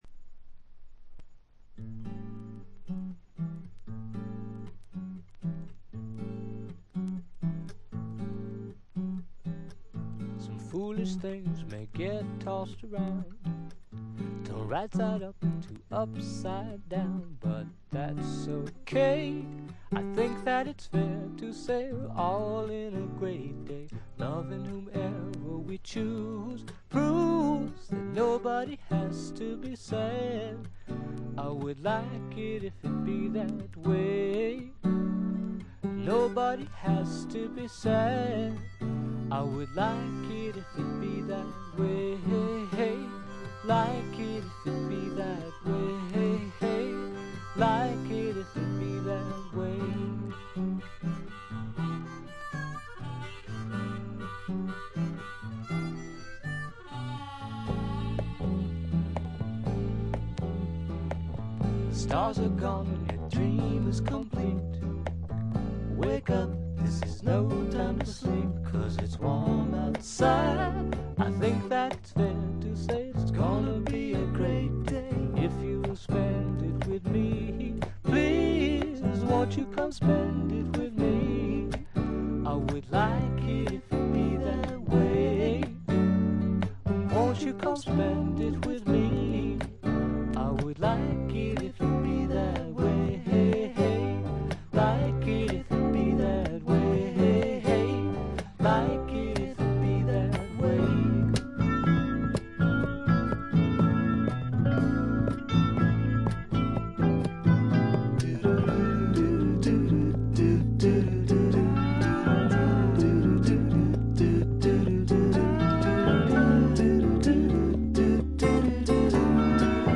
細かなチリプチや散発的なプツ音は少し出ますが良好に鑑賞できます。
内容はいかにもボストンらしく、フォーク、ドリーミー・ポップ、グッタイム・ミュージック等を下地にした実にごきげんなもの。
試聴曲は現品からの取り込み音源です。